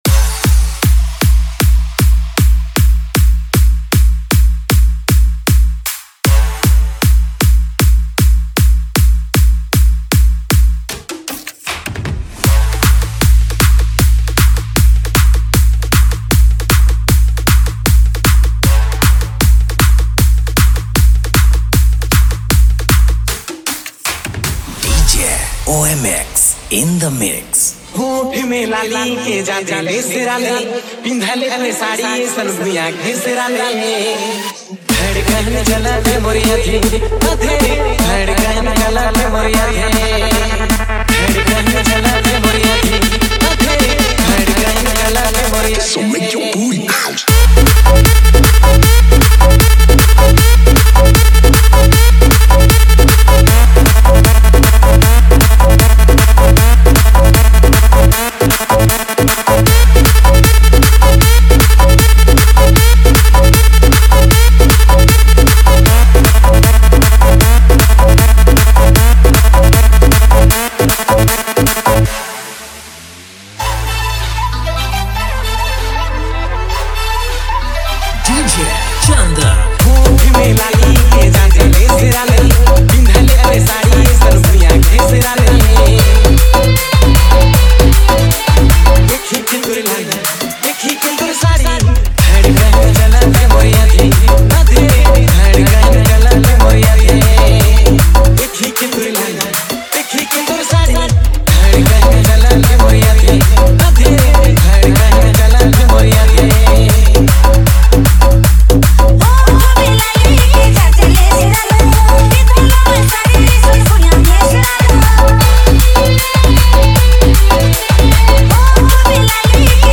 Category : Others Remix Songs